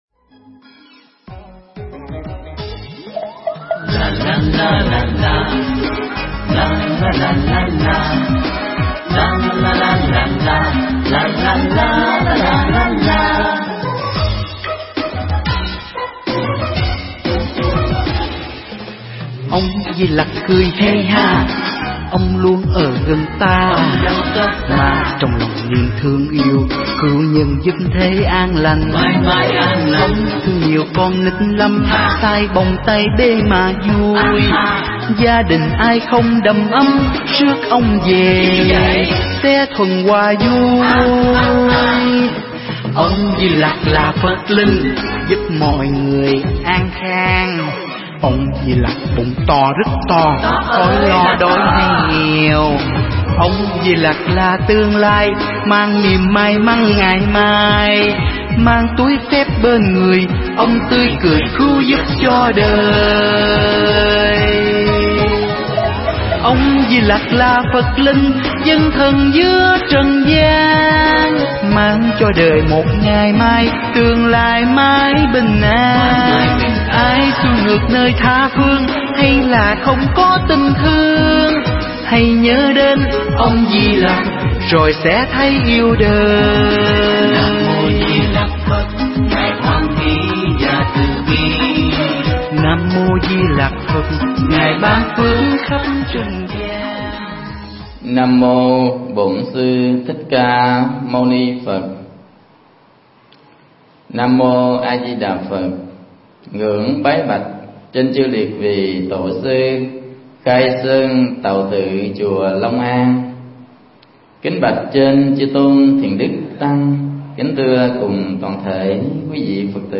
Mp3 Thuyết Pháp Hai Nụ Cười Qua Hình Tượng Ngài Di Lạc
giảng tại Chùa Long An